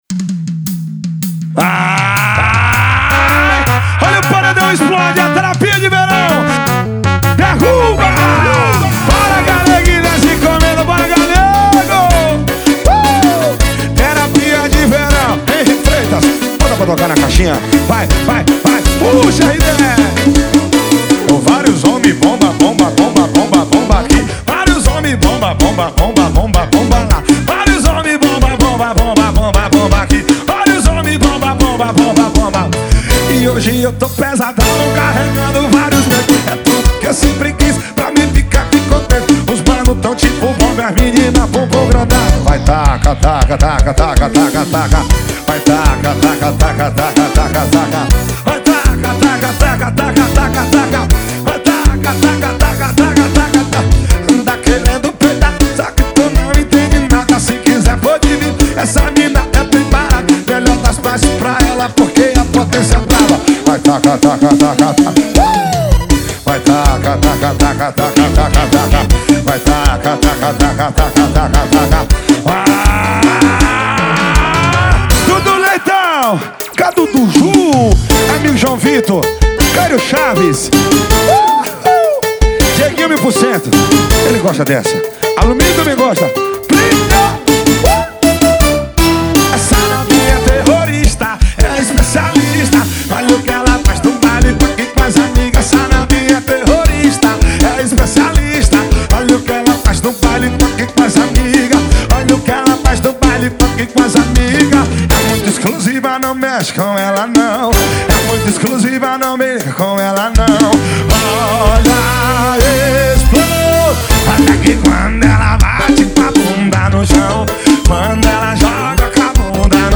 2024-02-14 18:37:04 Gênero: Forró Views